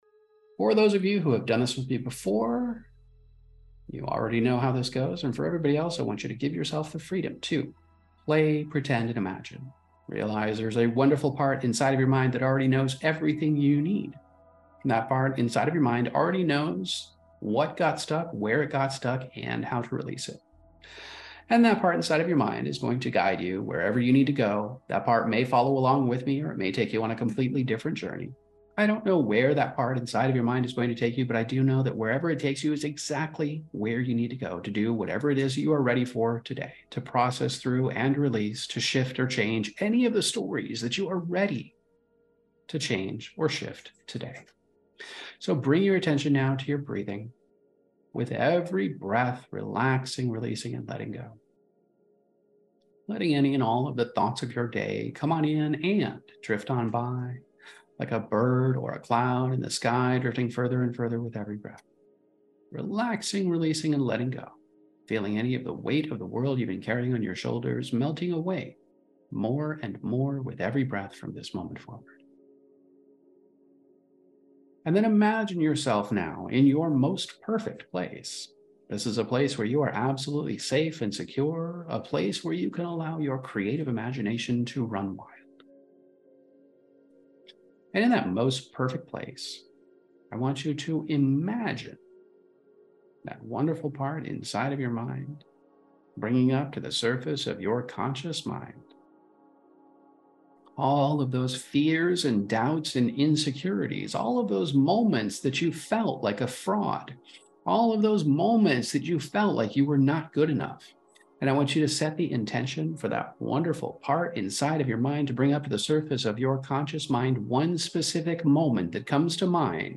Emotional Optimization™ Meditations